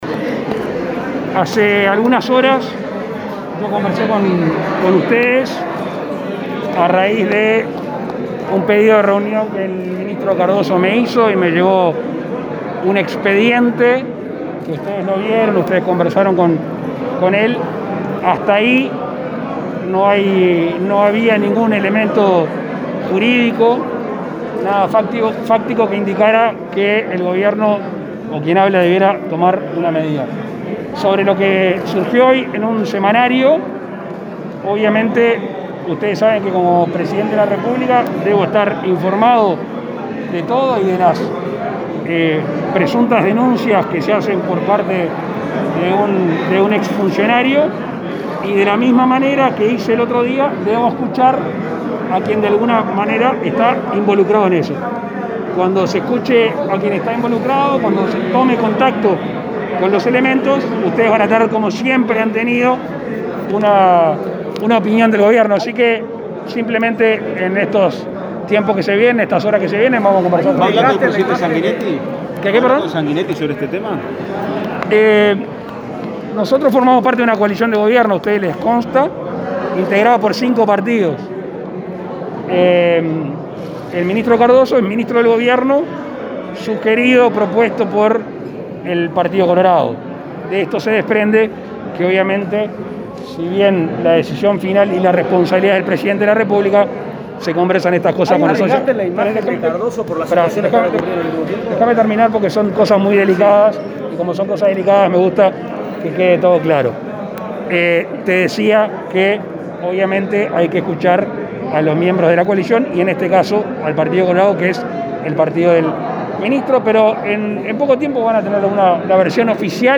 Declaraciones del presidente de la República, Luis Lacalle Pou
Tras participar en el lanzamiento de Organización Nacional de Deporte Infantil, en Flores, el mandatario realizó declaraciones a los medios